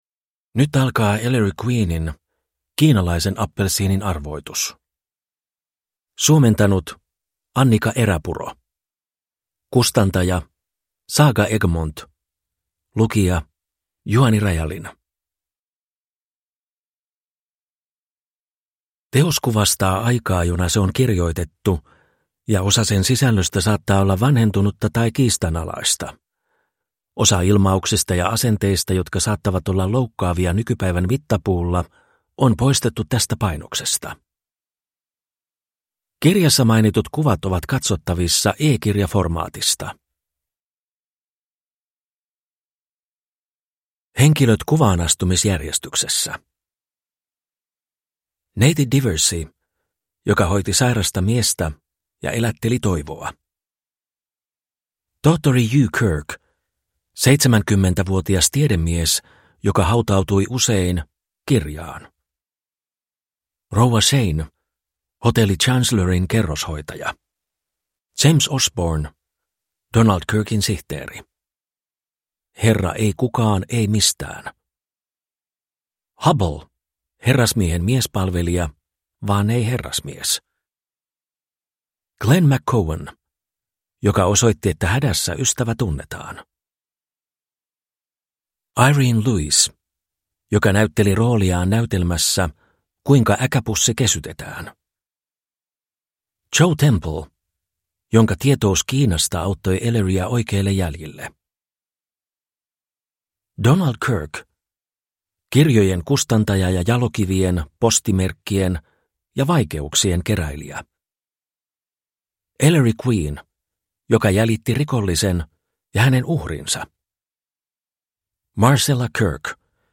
Kiinalaisen appelsiinin arvoitus / Ljudbok